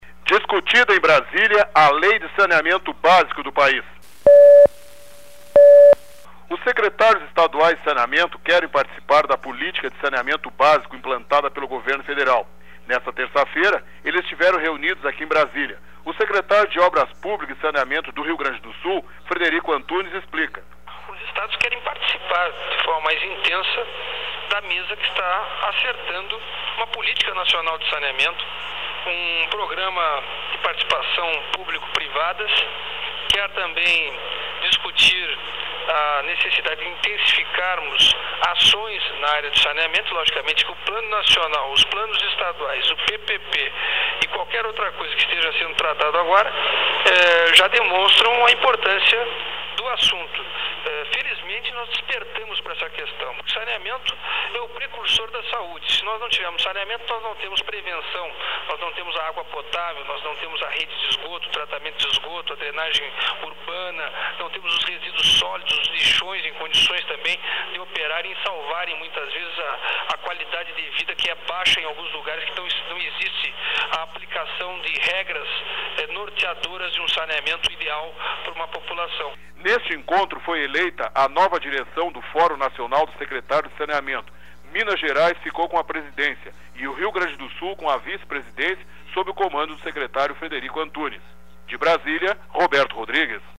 Os secretários estaduais de saneamento querem participar da política de sanemaneto básico implantada pelo governo federal. (Sonora: Secretário Estadual de Obras - Frederico Antunes)Local: Brasília - DFDuração: 00:01:33